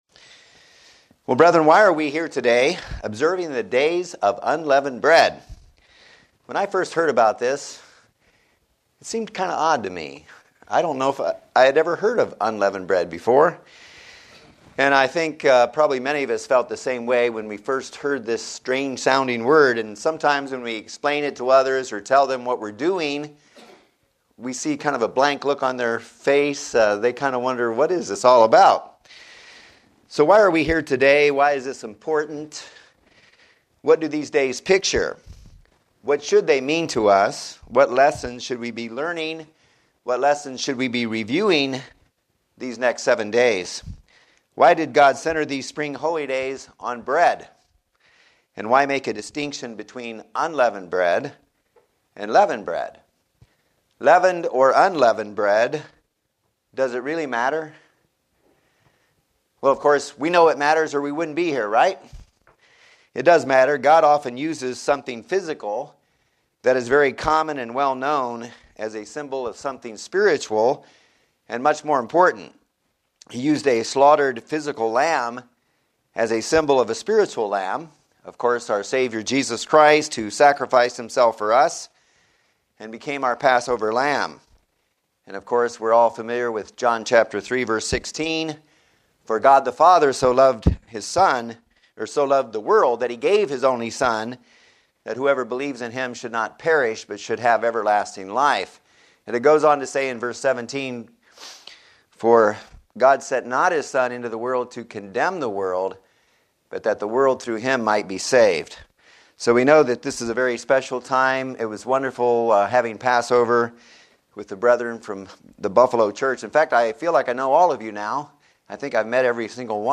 The Spiritual differance between unleavened and leavened bread. sermon Transcript This transcript was generated by AI and may contain errors.